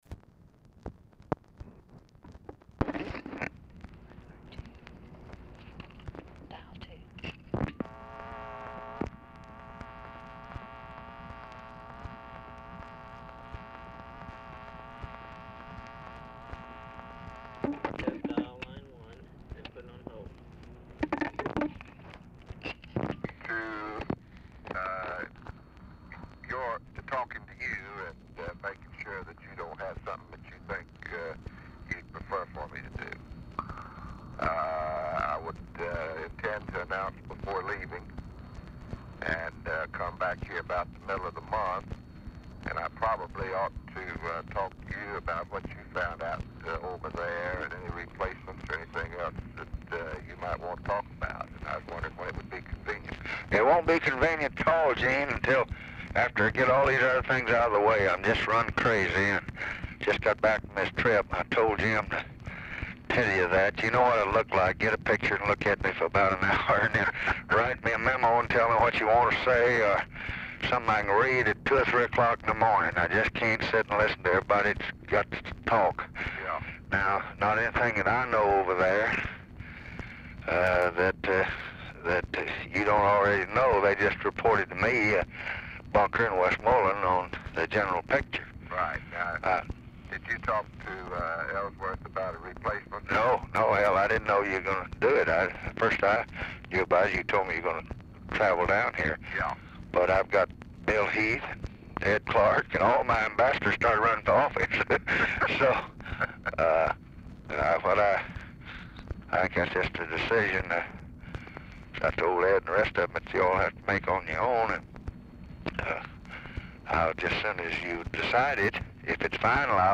Title Telephone conversation # 12510, sound recording, LBJ and EUGENE LOCKE, 12/27/1967, 9:45AM Archivist General Note "12/27/1967 LOCKE?"
RECORDING STARTS AFTER CONVERSATION HAS BEGUN
Format Dictation belt
LBJ Ranch, near Stonewall, Texas